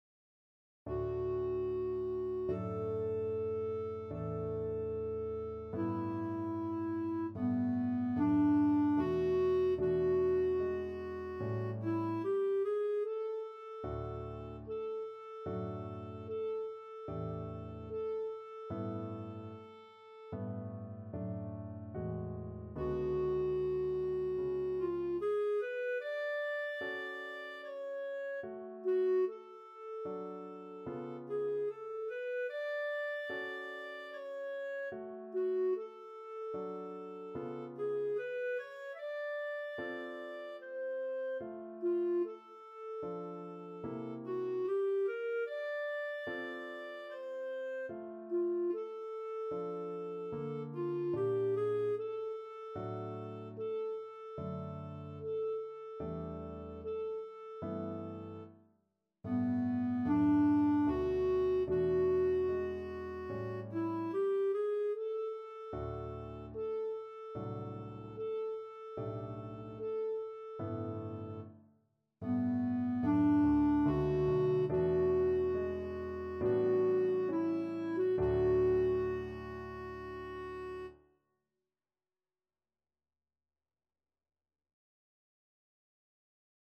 4/4 (View more 4/4 Music)
B4-E6
Classical (View more Classical Clarinet Music)